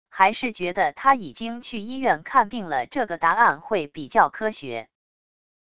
还是觉得他已经去医院看病了这个答案会比较科学wav音频生成系统WAV Audio Player